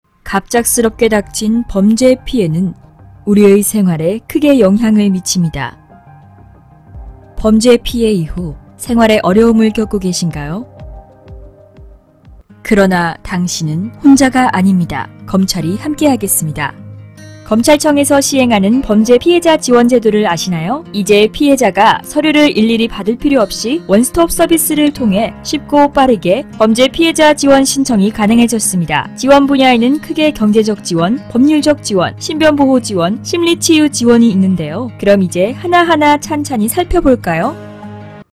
韩语106T-C 女声 宣传片专题片 略成熟 大气沉稳 沉稳|娓娓道来|科技感|积极向上|时尚活力|神秘性感|亲切甜美|素人